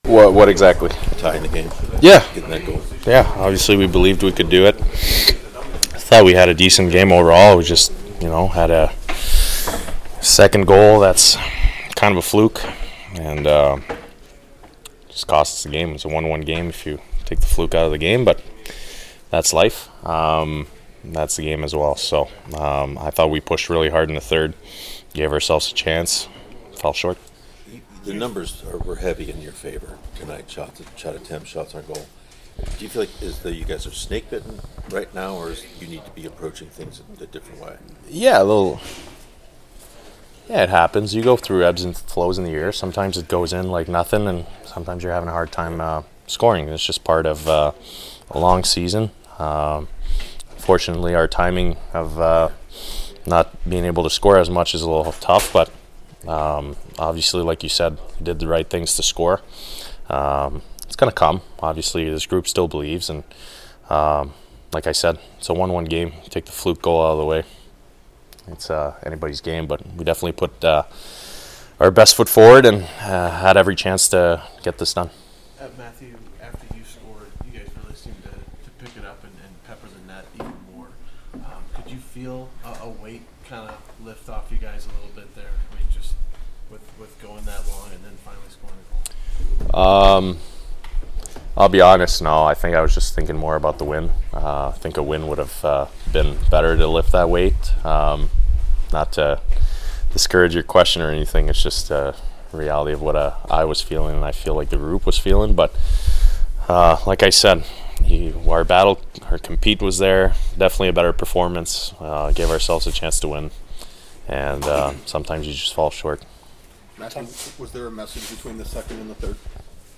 MAR. 17, 2025  NATIONWIDE ARENA  VS. NEW JERSEY DEVILS